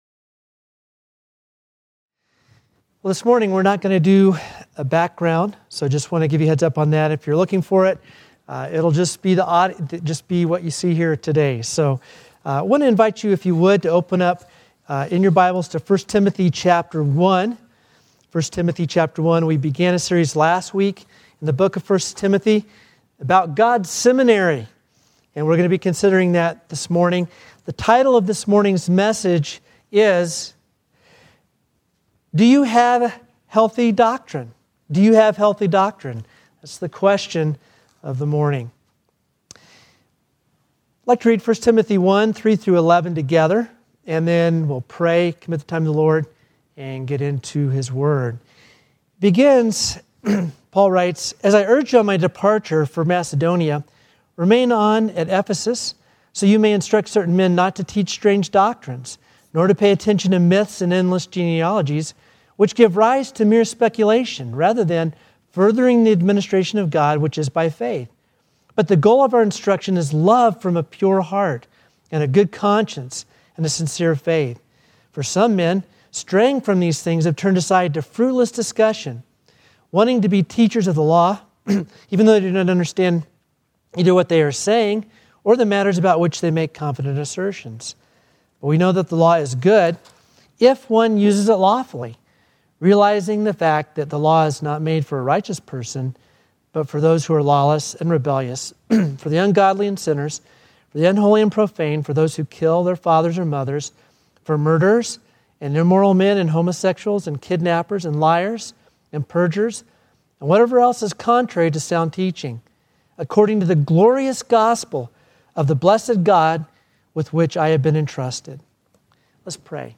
3-22-Sermon-1.mp3